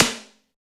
TECHTOM MID.wav